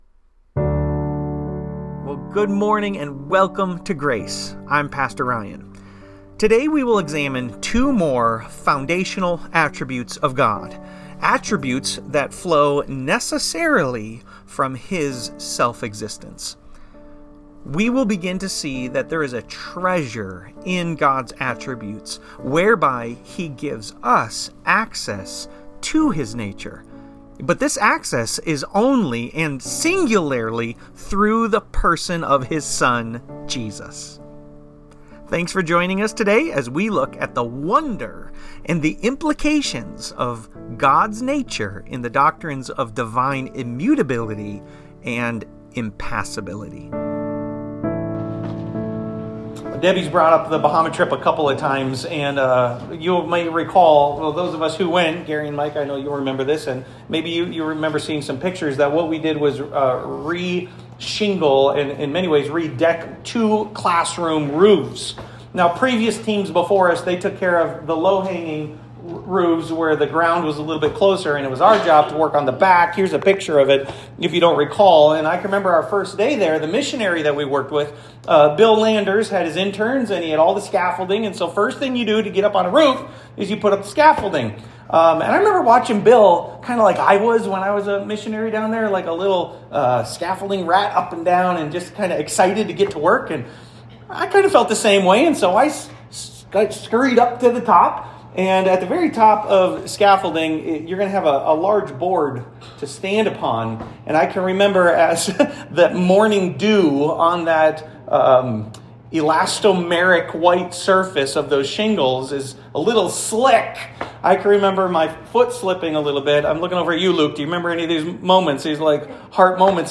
Immutability & Impassability – Grace Church